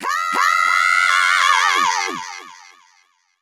Techno / Voice